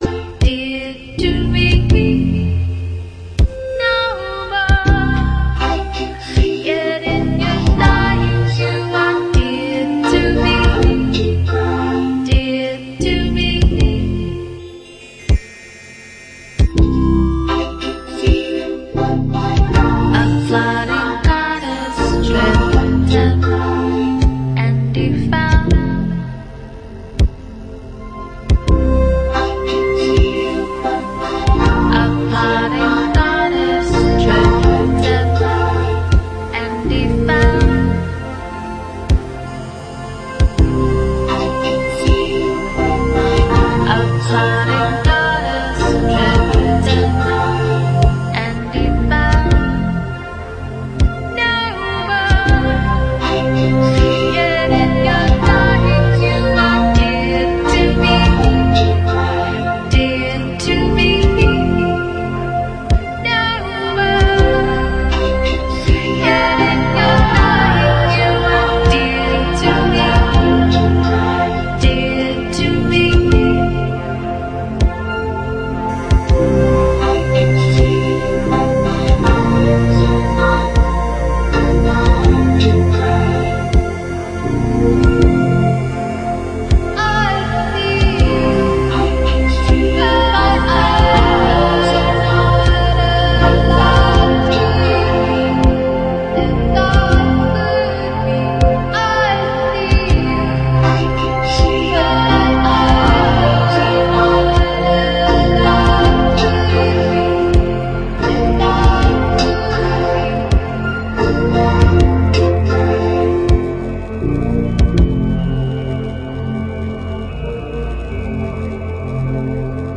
uma espécie de bidimensão da pop que tenta criar